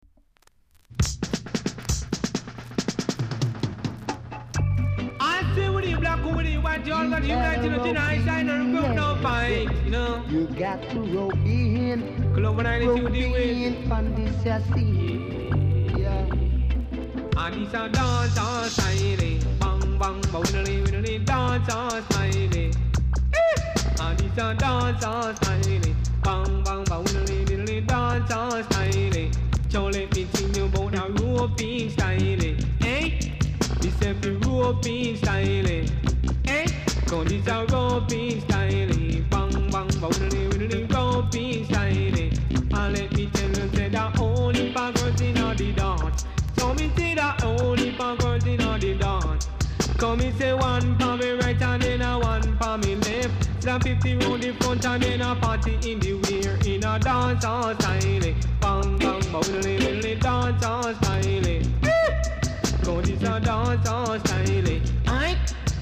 ※出だしで少しチリチリします。ほかチリ、パチノイズが少しあります。